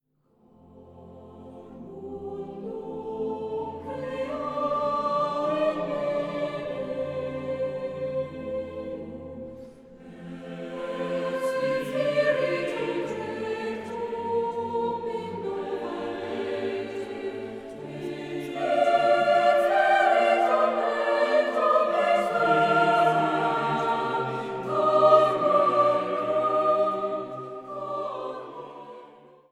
für vier- bis sechsstimmig gemischten Chor a cappella